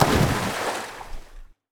waterimpact02.wav